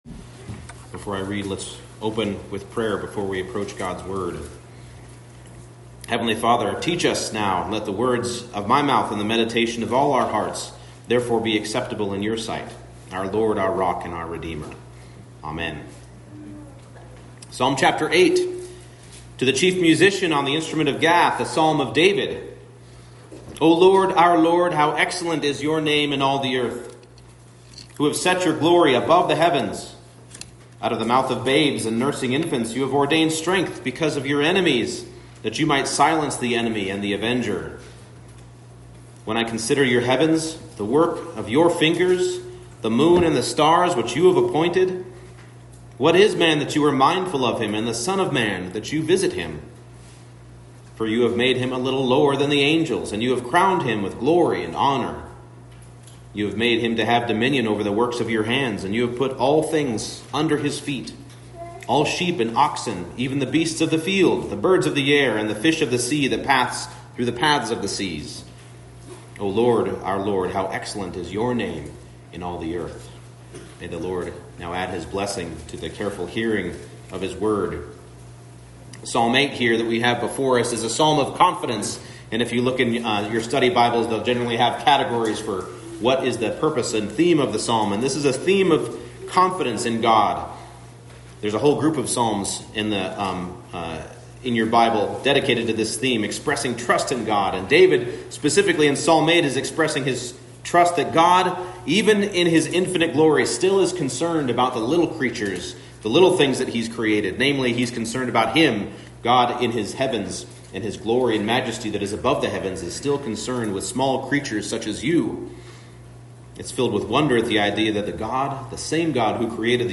Psalm 8 Service Type: Morning Service The excellent name of God is shown in man